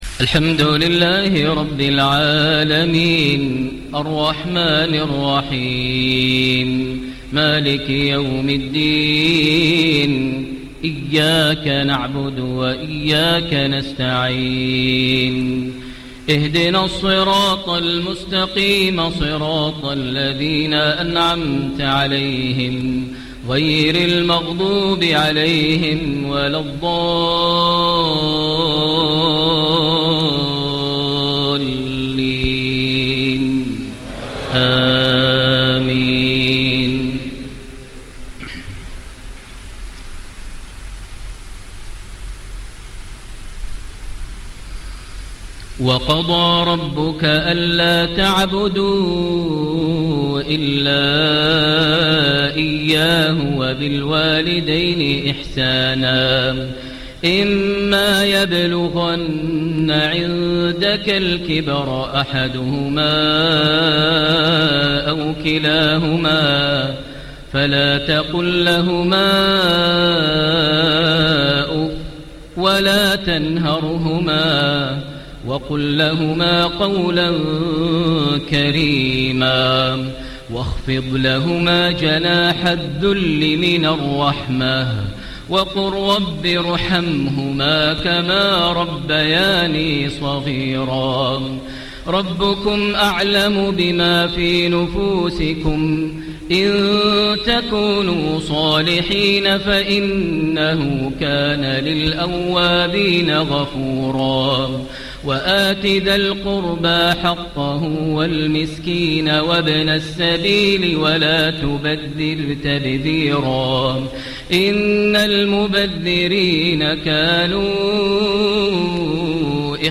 Isha Prayer Surah AlIsra‘ > 1436 H > Prayers - Maher Almuaiqly Recitations